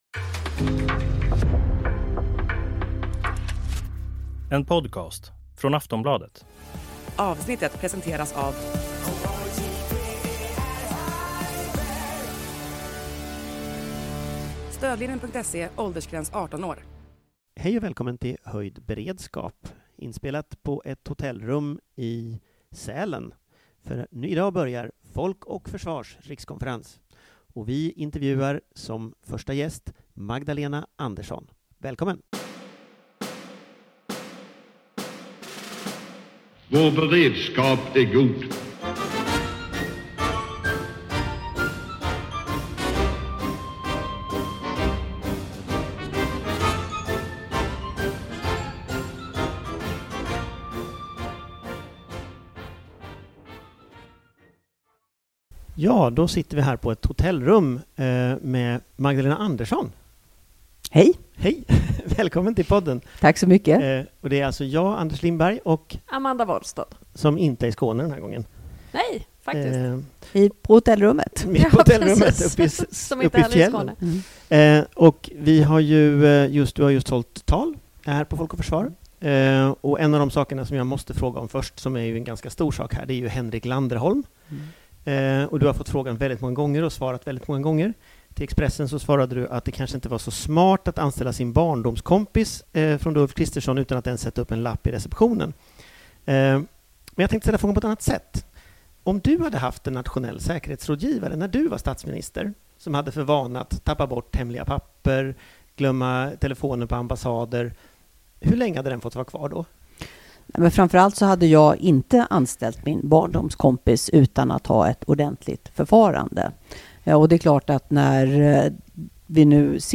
Höjd beredskap möter Magdalena Andersson på Folk och försvars rikskonferens 2025 i Sälen